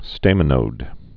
(stāmə-nōd, stămə-) also stam·i·no·di·um (stāmə-nōdē-əm, stămə-)